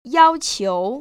[yāoqiú] 야오치우  ▶